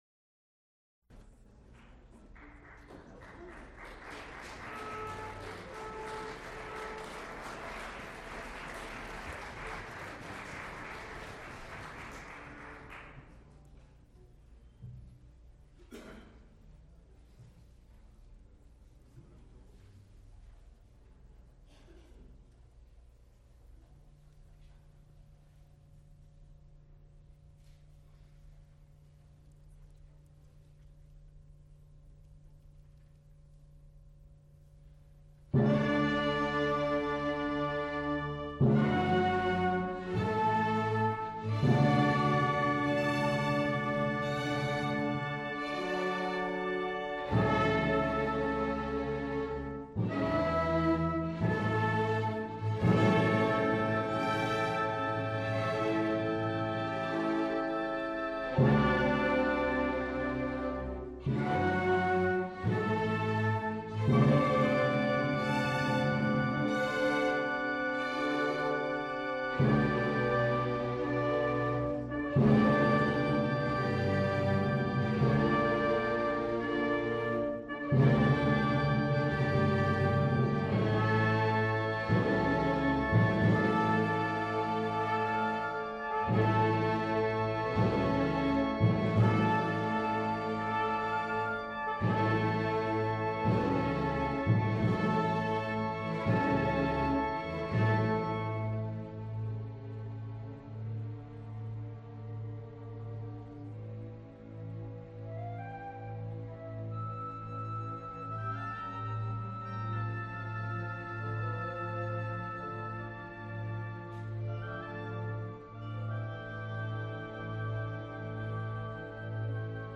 Recorded live October 23, 1979, Schenley Hall, University of Pittsburgh.
English horn and trumpet with string orchestra